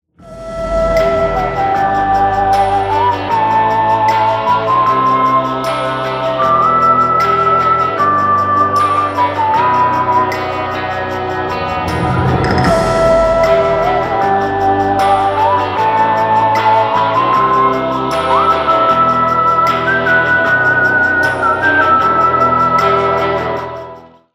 • Качество: 320, Stereo
свист
спокойные
без слов
Cover
американская грув-метал-группа